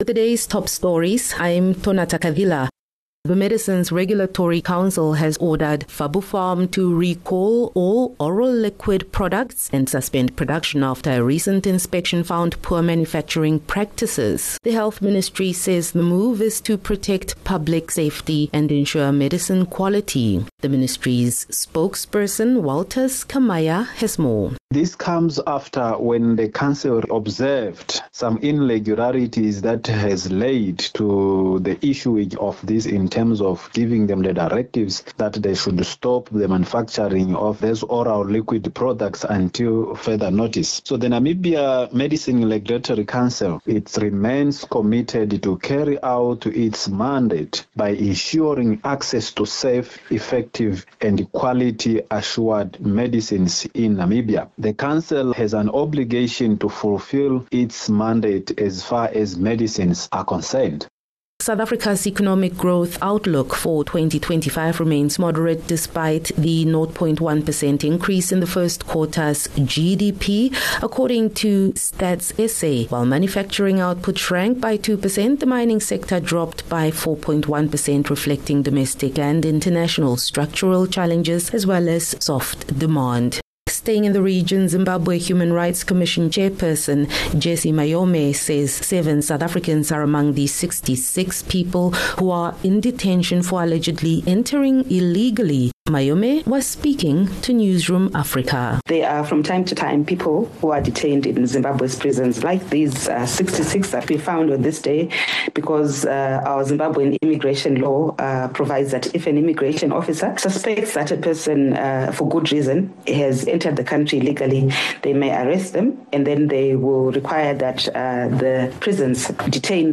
Daily bulletins from Namibia's award winning news team. Independent, Accurate, and On-Time